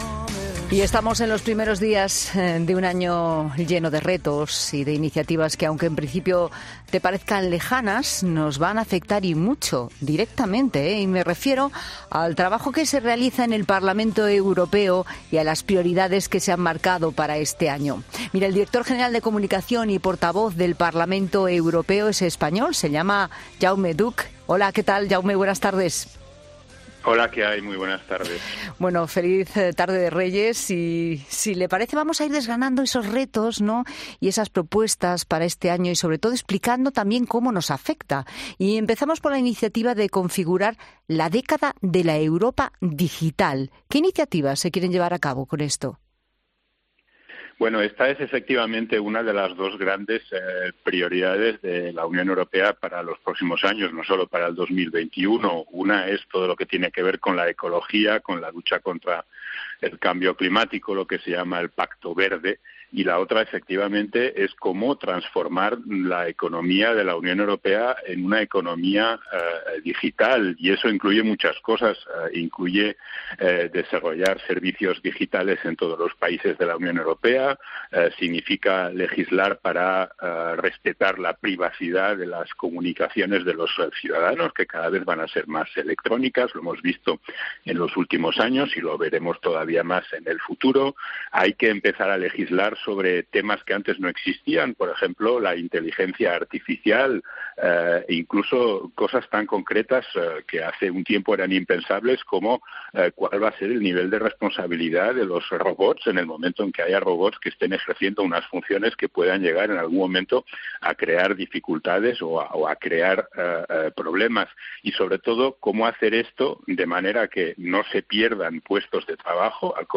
En 'La Tarde' hemos hablado con el Director General de Comunicación y portavoz del Parlamento Europeo, Jaume Duch, que ha defendido que las grandes prioridades de la Unión Europea en los próximos años tienen que ver con el desarrollo del a tecnología y la lucha contra el cambio climático. Asimismo es de vital importancia la transformación de la economía de la UE en la economía digital.